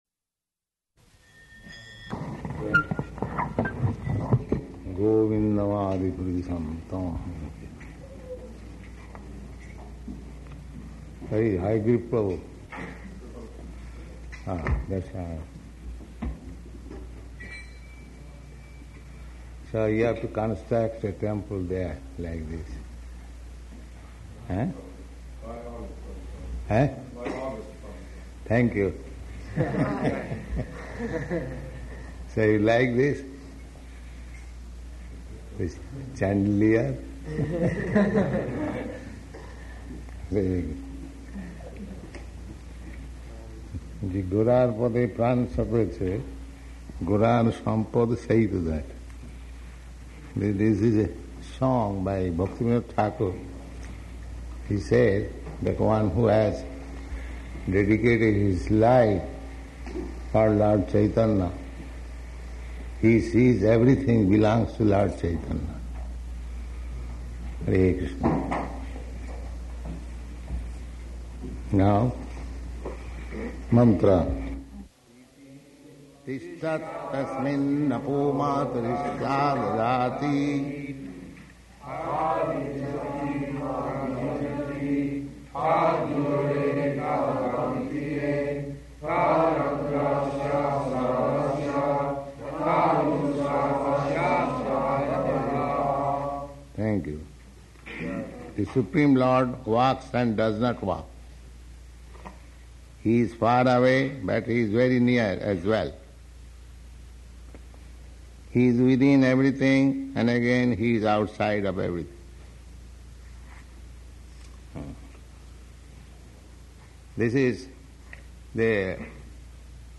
Śrī Īśopaniṣad, Mantra 5 --:-- --:-- Type: Sri Isopanisad Dated: May 7th 1970 Location: Los Angeles Audio file: 700507IP-LOS_ANGELES.mp3 Prabhupāda: Govindam ādi-puruṣaṁ tam ahaṁ bhajāmi ** .